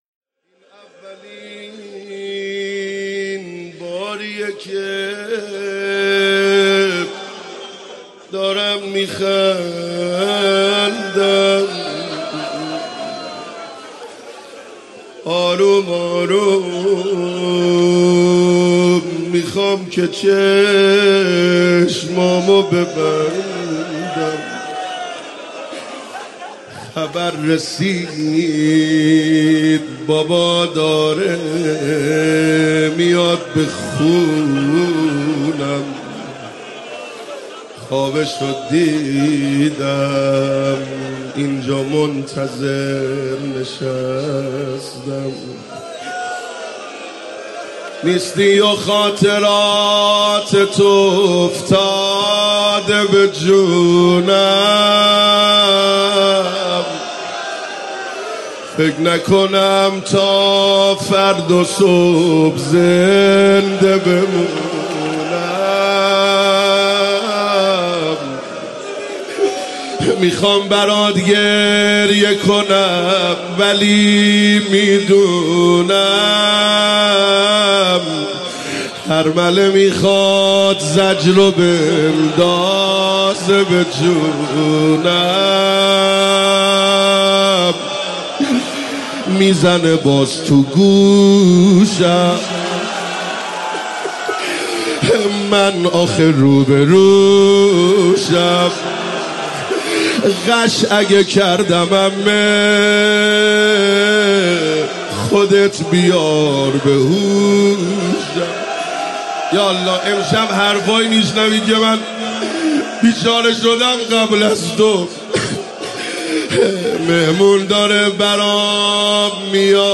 روضه حضرت رقیه - این اولیه باریه که